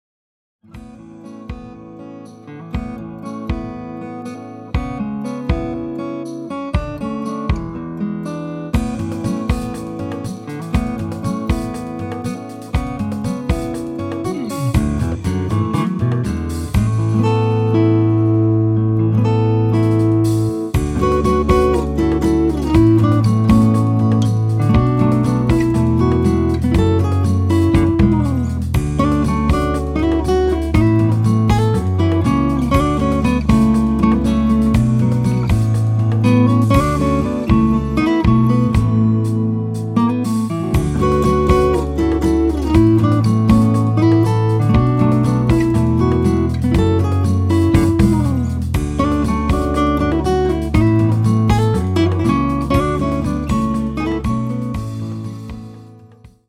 10時の音楽のひととき [その他のファイル／10.34MB] 録音音声